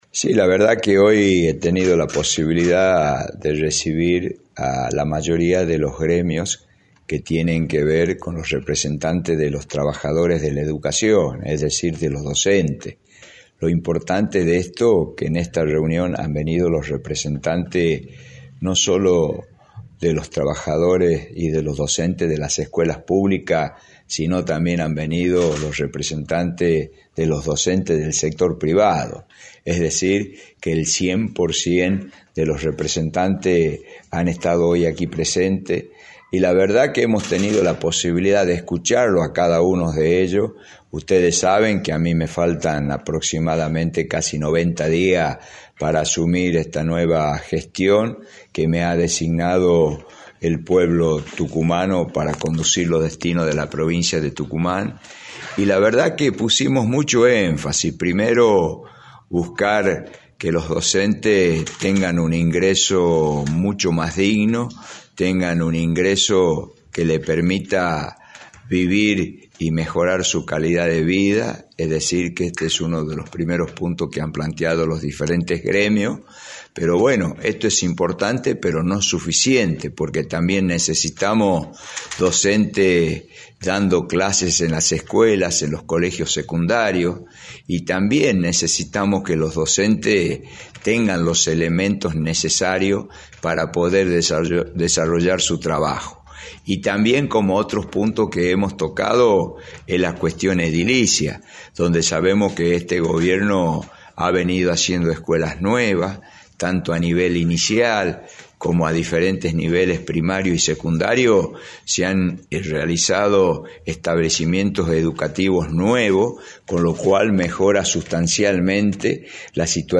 “Hemos tenido la oportunidad de escucharlos a cada uno de ellos, vamos a buscar que los docentes tengan un ingreso mucho más digno, que tengan un ingreso que les permita vivir y mejorar su calidad de vida, esto es importante pero no es suficiente, necesitamos que estén dando clases y que tengan las herramientas para dictar las clases” señaló Osvaldo Jaldo en entrevista para Radio del Plata Tucumán, por la 93.9.